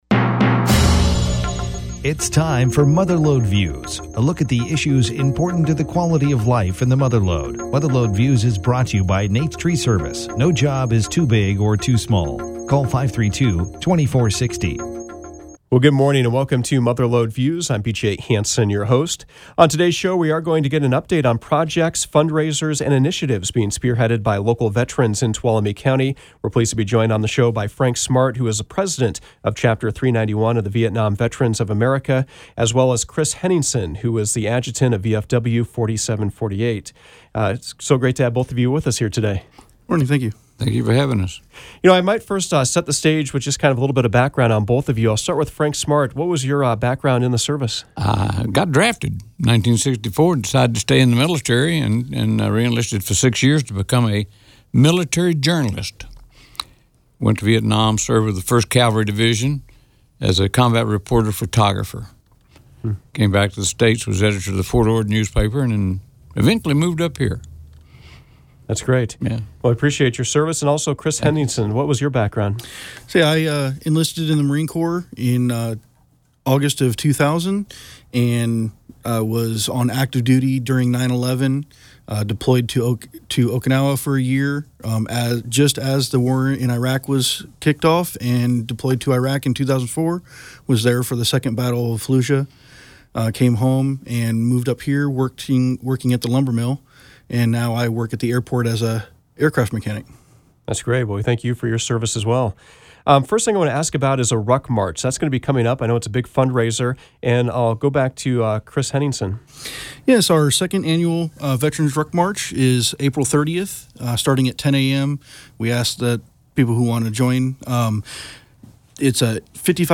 Mother Lode Views featured local leaders with the Vietnam Veterans of America Post 391 and the Veterans of Foreign Wars Post 4748. Topics included the upcoming Vets Ruck March, Post 4748’s taking over operations of the Tuolumne Lumber Jubilee, a new Honor Guard in the community, and the roles of the different Veterans organizations in the community.